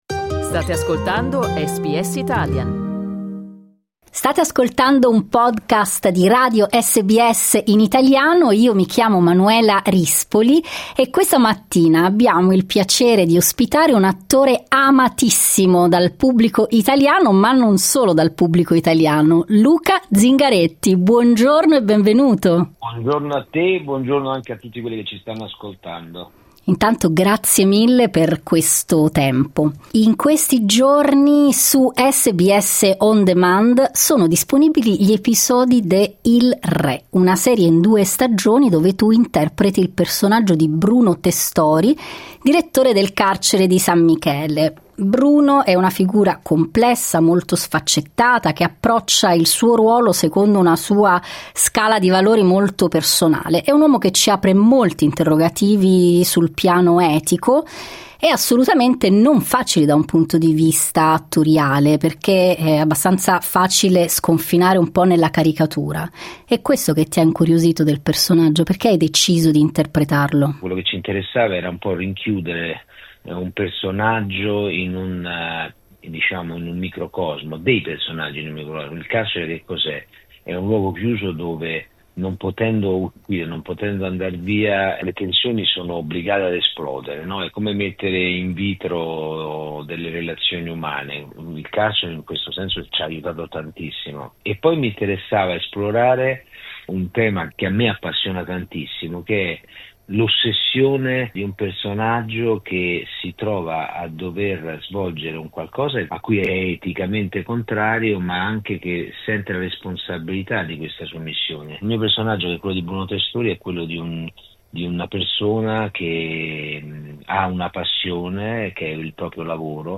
Dietro le quinte de "Il Re", intervista a Luca Zingaretti